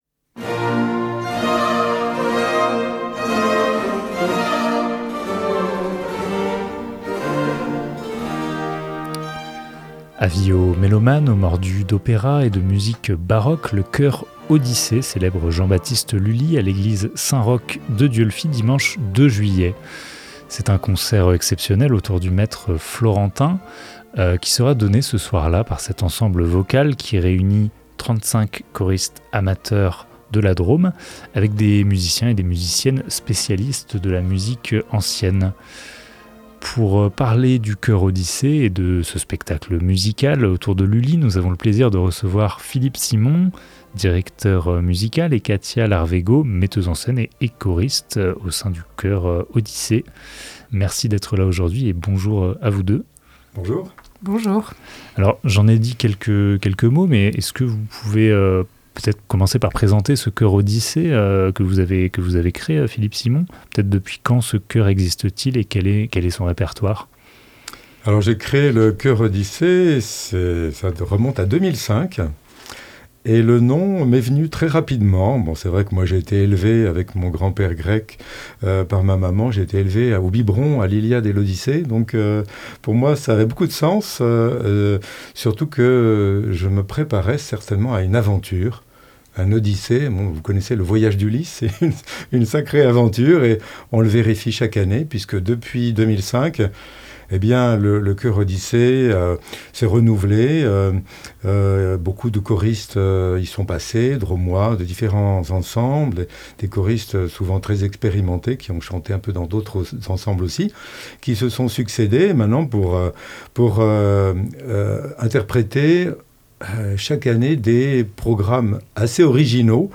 Interview
Musique : Extrait d’une répétition du spectacle Les Captifs Jean-Baptiste Lully – Marche pour la cérémonie des Turcs Réalisation